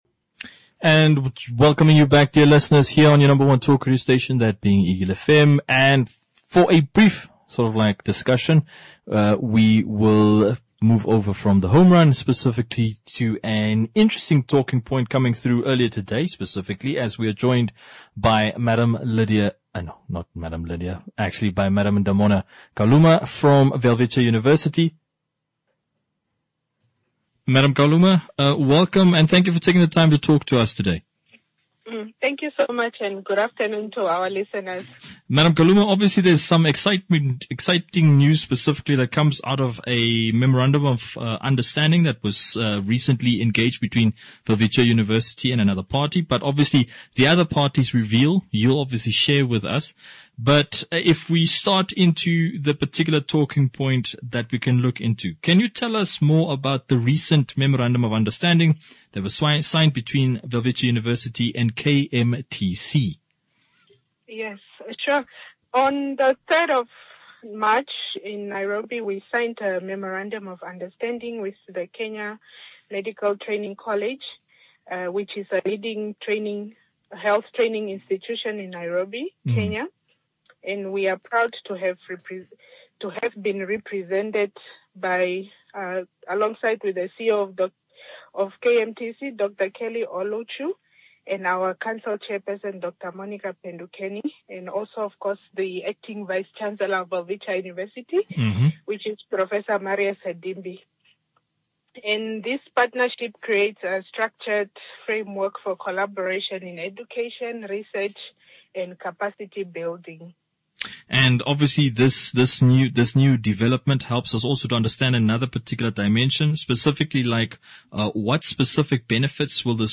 WELWITCHIA AND KMTC SIGN HEALTH PARTNERSHIP INTERVIEW.
WELWITCHIA UNIVERSITY INTERVIEW 6 MARCH 2026.mp3